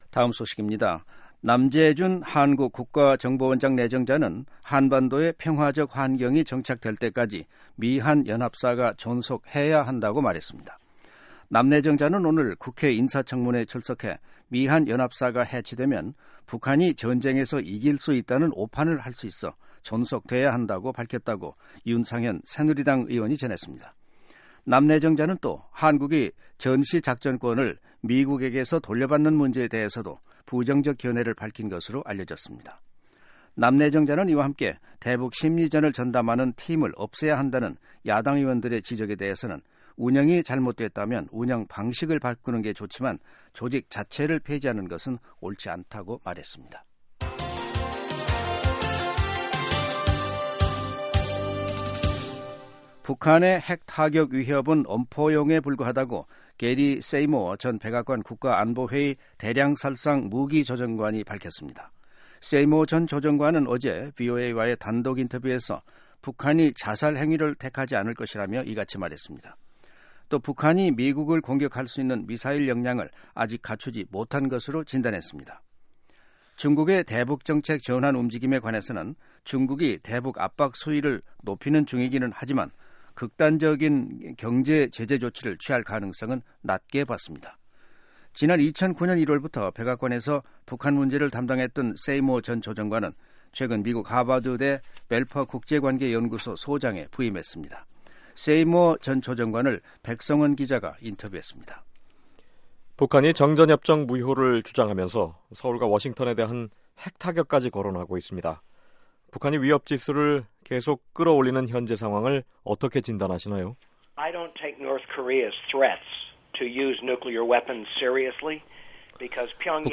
[인터뷰] 게리 세이모어 전 백악관 국가안보회의 대량살상무기 조정관